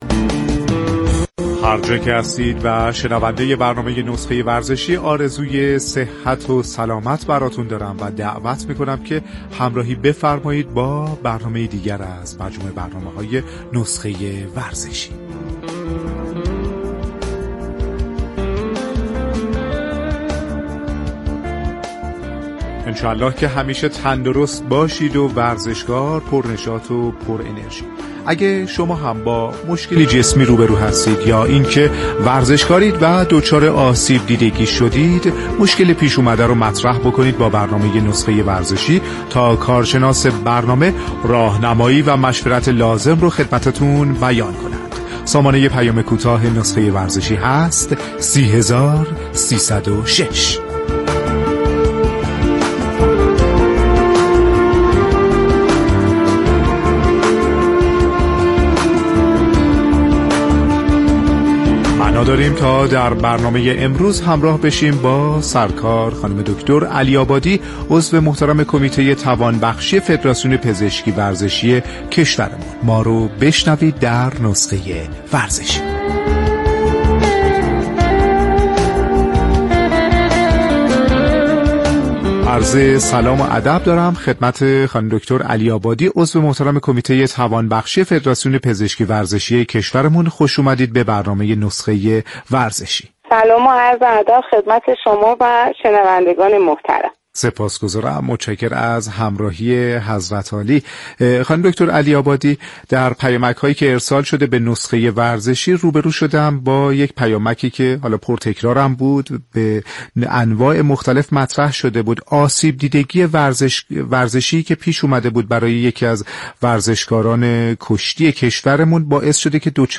در گفت وگو با برنامه نسخه ورزشی رادیو ورزش